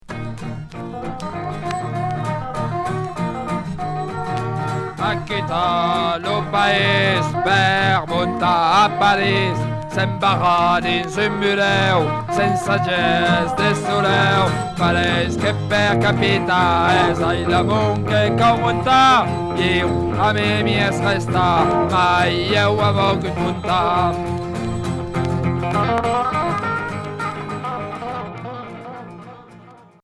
Folk " punk " occitan Unique EP